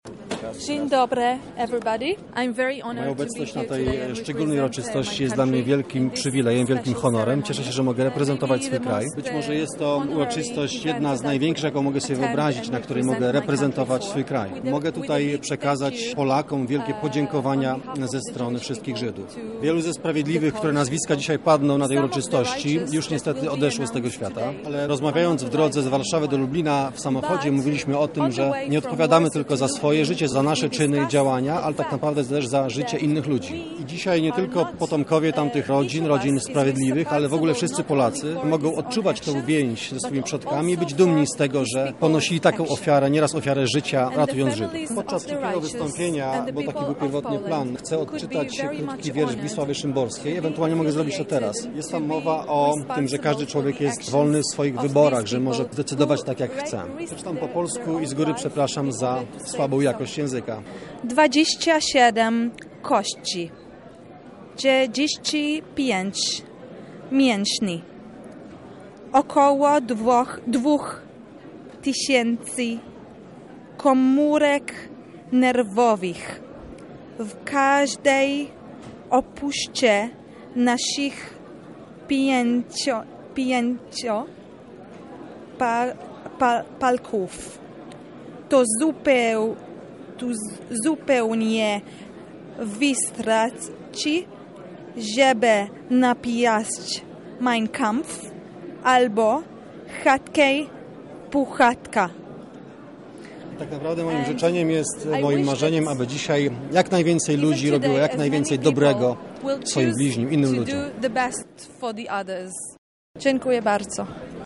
W Lublinie odbyła się gala upamiętniająca tych bohaterów, mieszkających na teranie naszego regionu. Gościem specjalnym była Attache Kulturalna Ambasady Izraela w Polsce, Hadass Nisan.
sprawiedliwiatache.mp3